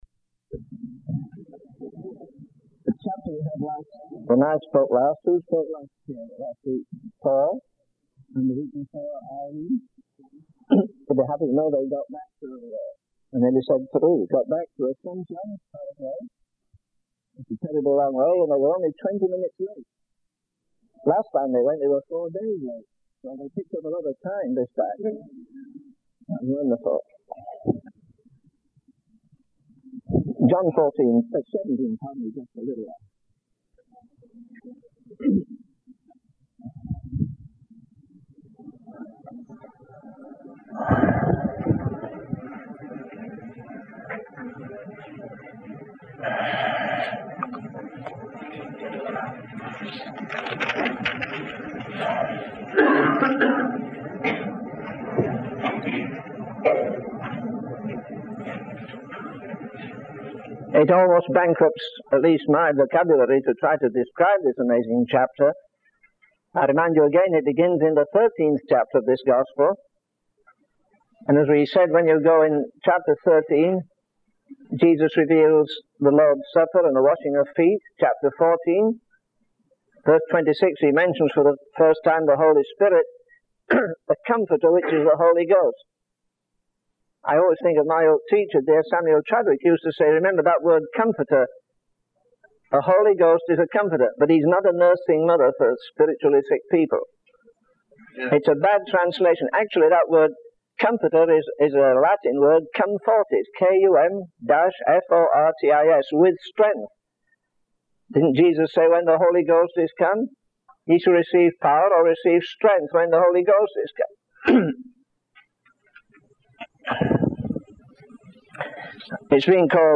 In this sermon, the speaker reflects on the hardships and sufferings that the apostle Paul endured in his ministry.